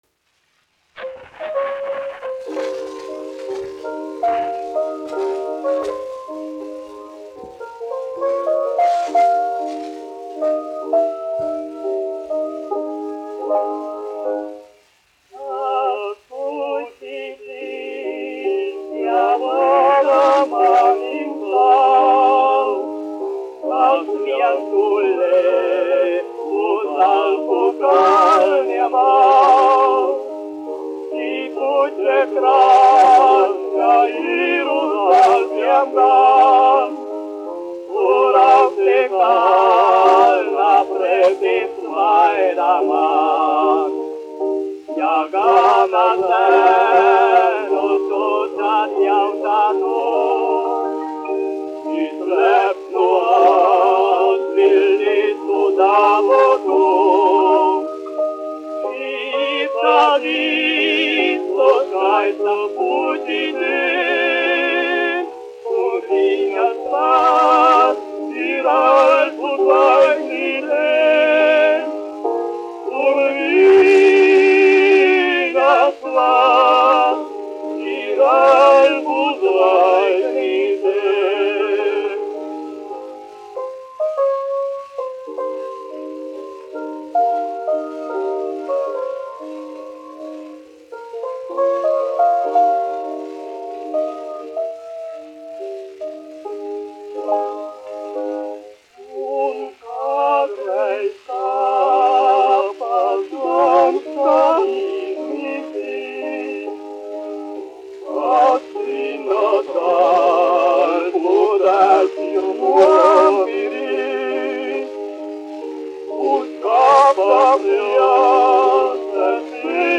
1 skpl. : analogs, 78 apgr/min, mono ; 25 cm
Vokālie dueti
Rīgas Jaunā Latviešu teātra dziedātāji
Skaņuplate